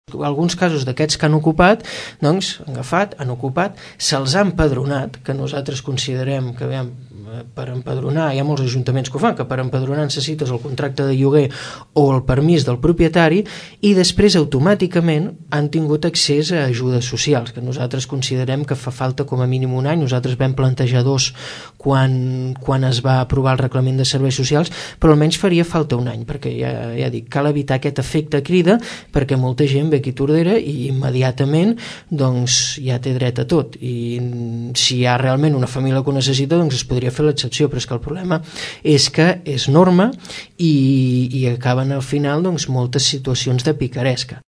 En una entrevista al programa L’entrevista amb els polítics de Ràdio Tordera, Xavier Martin qualifica de ”discret” el balanç de la legislatura perquè, segons el regidor, ”l’Ajuntament ha tingut encerts i errors en la gestió municipal”.